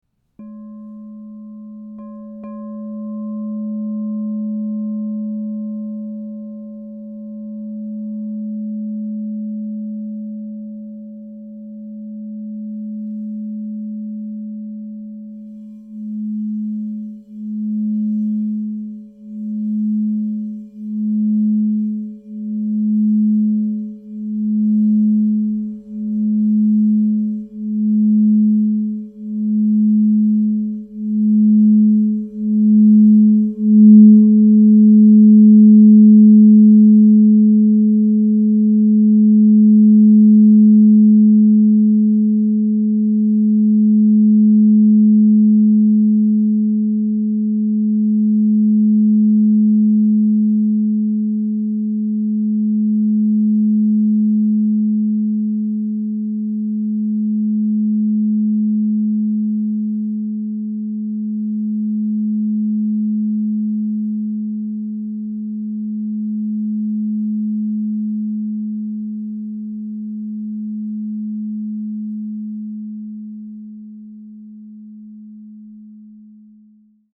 Diamond and Platinum Infused Crystal Singing Bowl - 10 inches, Key of G# 528 Hz